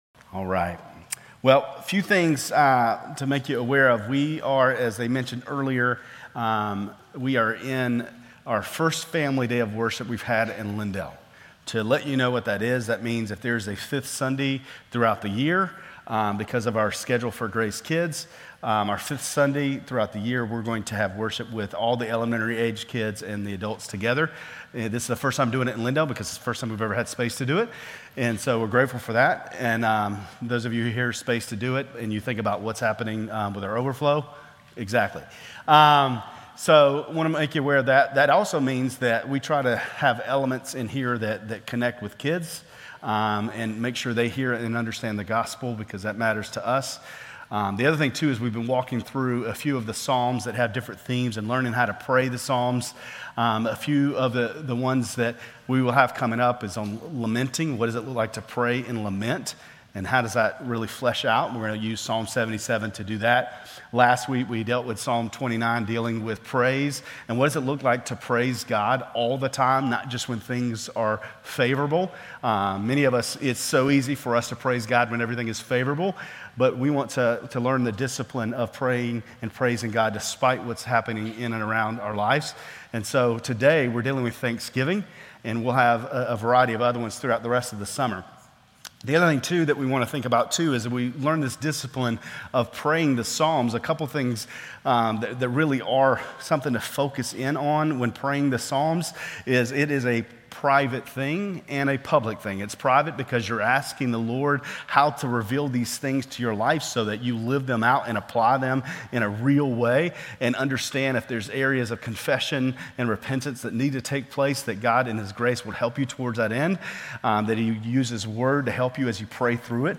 Grace Community Church Lindale Campus Sermons Psalm 100 - Thanksgiving Jul 01 2024 | 00:26:30 1x 00:00 / 00:26:30 Subscribe Share RSS Feed Share Link Embed